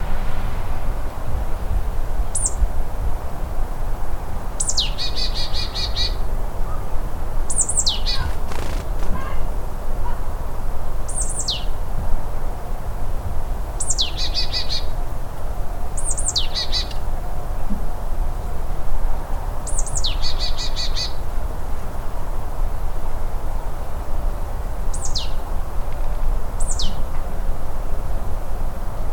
But I did get my second (good enough) recording of a bird 🙂
A Marsh Tit made some noise after a Kestrel landed in a tree in the garden.